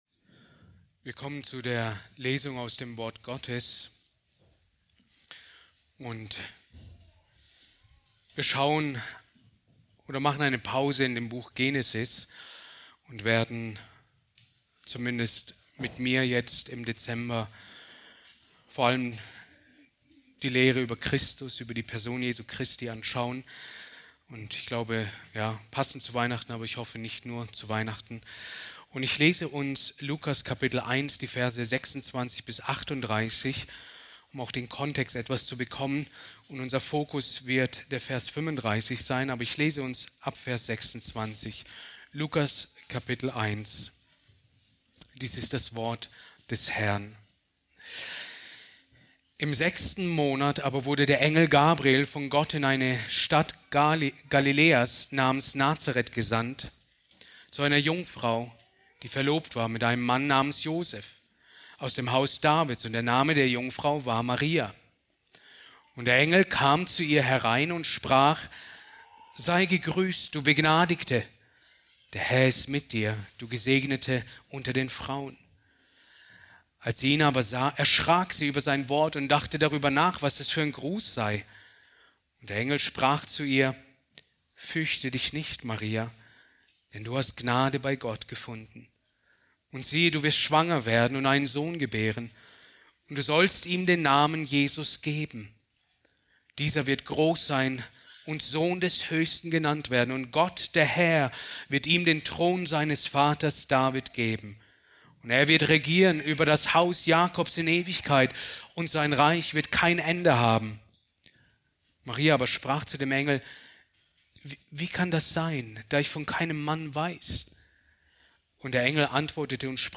Predigt aus der Serie: "Christologie"